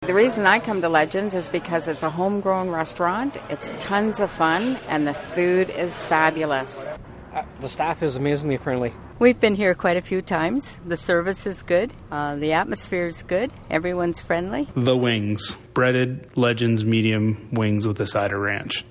june-7-legends-voicer.mp3